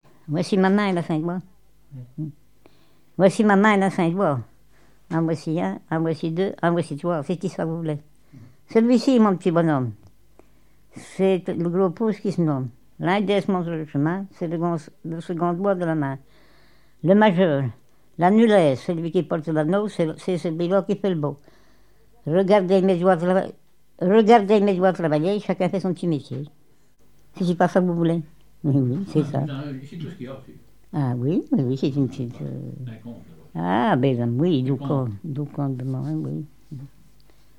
enfantine : comptine ; formulette enfantine : jeu des doigts
Chansons et formulettes enfantines
Pièce musicale inédite